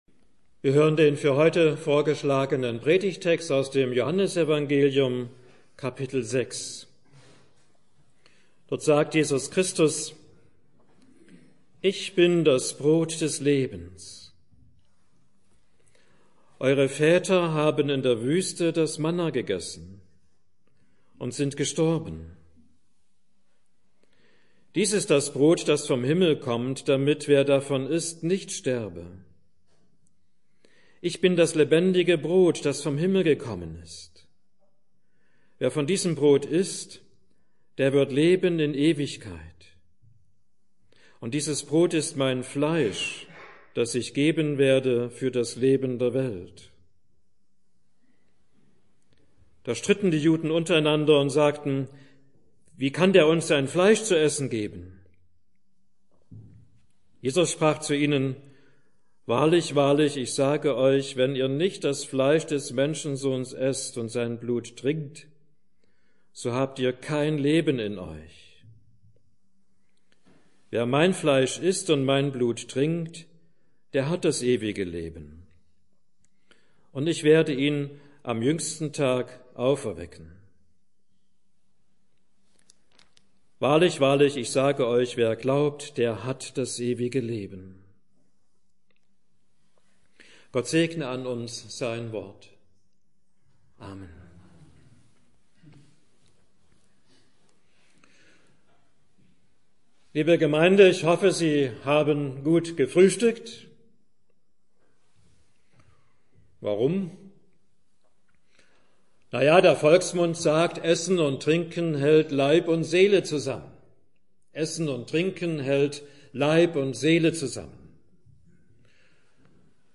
Mai 2015 Heruntergeladen 358 Mal Kategorie Audiodateien Predigten Schlagwörter Leib , Geist , Seele , Abendmahl , fleisch , joh 6 , johannes 6 , brot Beschreibung: Was unser Innerstes ernährt